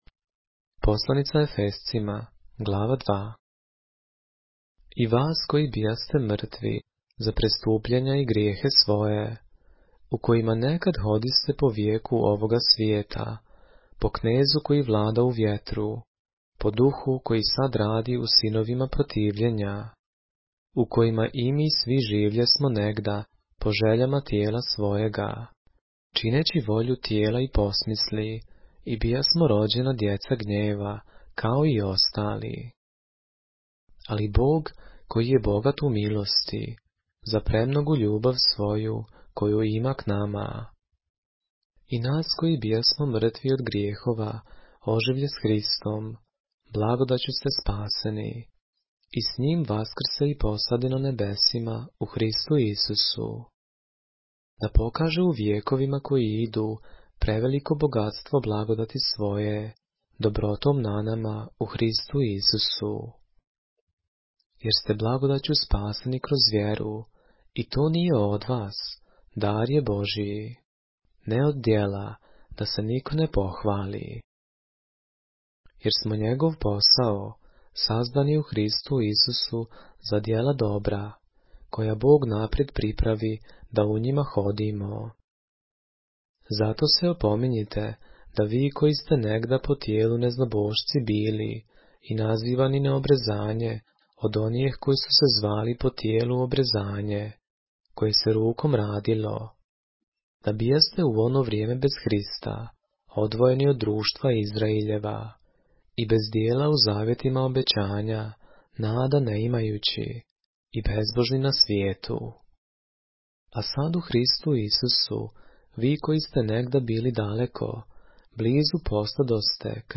поглавље српске Библије - са аудио нарације - Ephesians, chapter 2 of the Holy Bible in the Serbian language